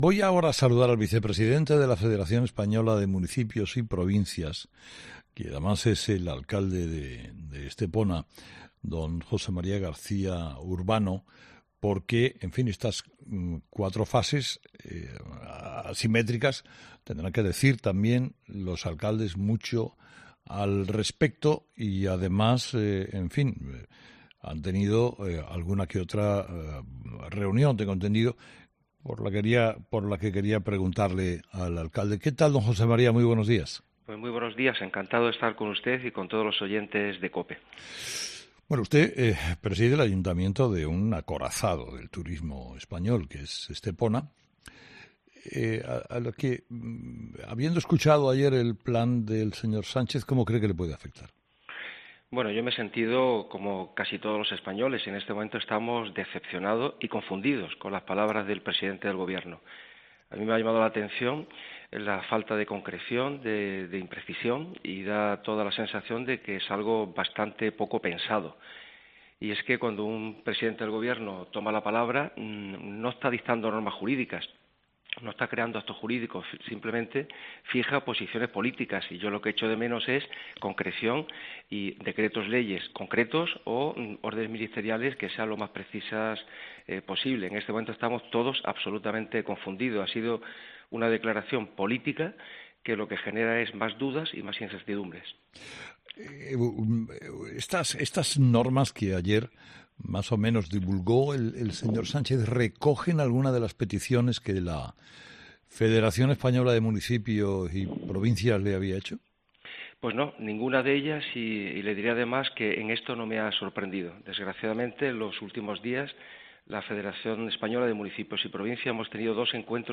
José María García Urbano, alcalde de Estepona y vicepresidente de la FEMP, cree que en el plan de desescalada del Gobierno falta concreción. "Está poco pensado", dice en COPE
Con Carlos Herrera